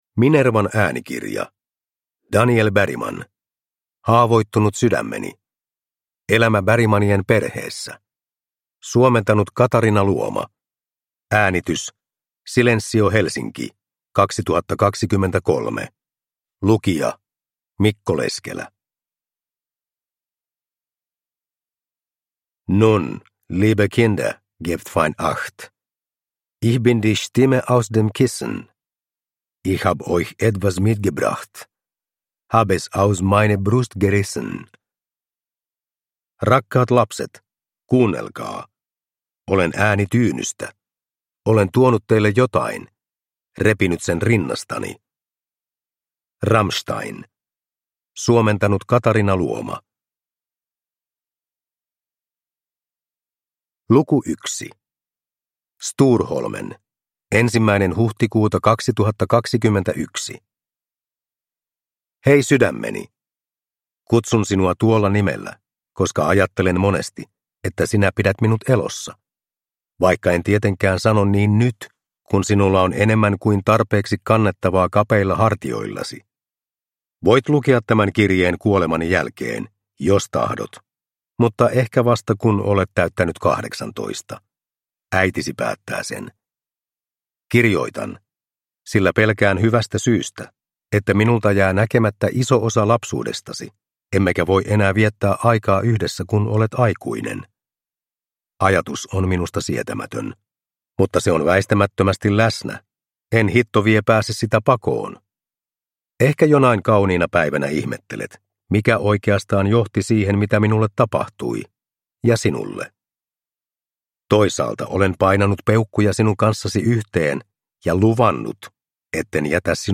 Haavoittunut sydämeni – Ljudbok – Laddas ner